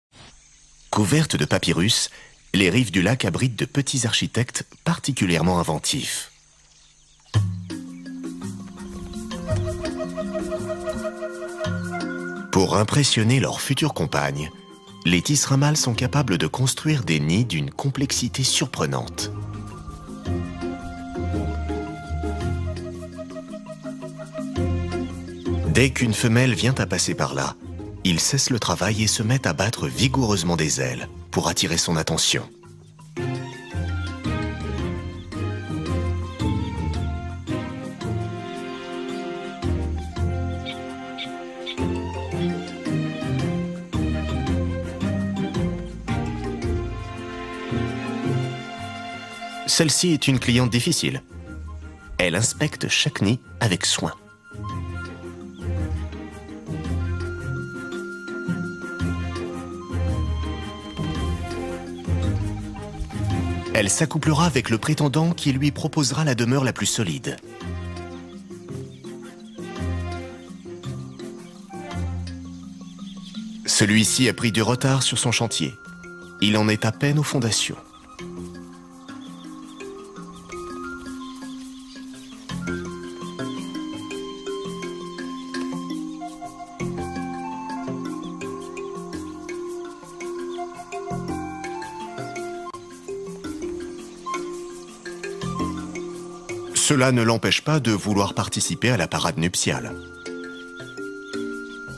Prestation voix-off pour "L'Afrique sauvage" : ton posé, explicatif et élégant
Explicatif, posé et amusé.
Documentaire « L’Afrique sauvage » diffusé sur France 5 et enregistré chez Cinécim.
J’ai opté pour une tonalité médium grave, pour captiver les téléspectateurs et les emmener dans ce voyage à travers l’Afrique sauvage.
Mon travail dans ce documentaire a été enregistré chez Cinécim, un studio réputé pour sa qualité d’enregistrement.